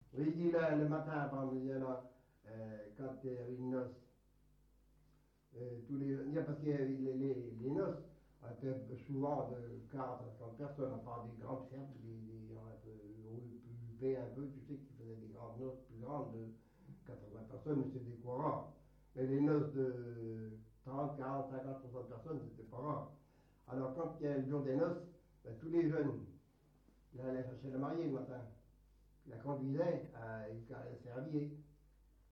Localisation Saint-Malô-du-Bois
Catégorie Témoignage